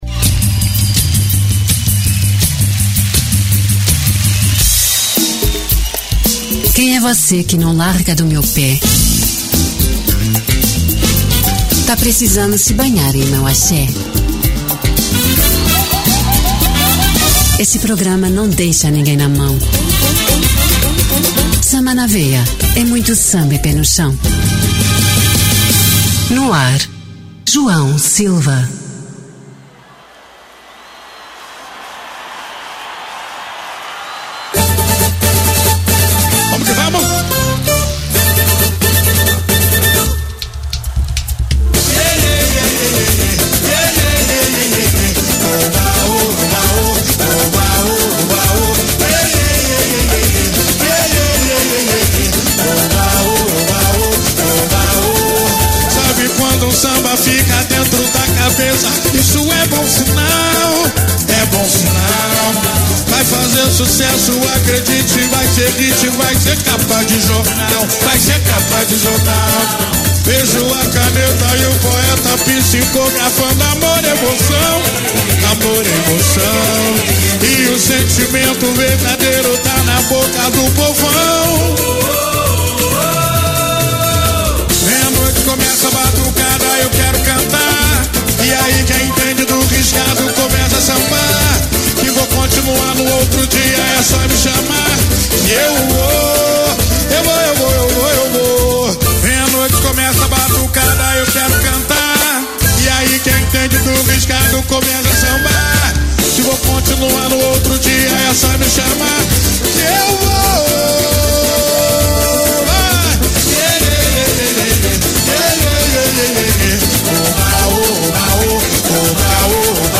O Samba de Raíz
Muito Samba e pé no chão!